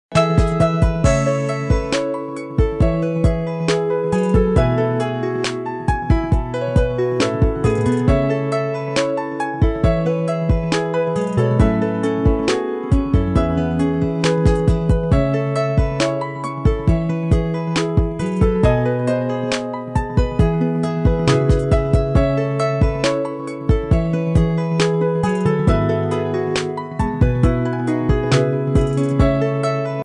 Pingos musicais estilizados